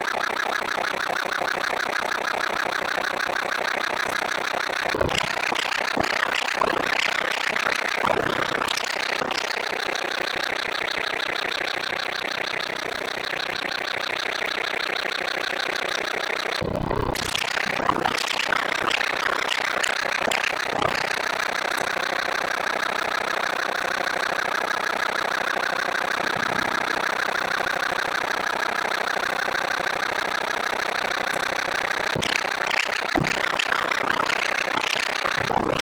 Audio Synthetisiert
KaffeSynth.wav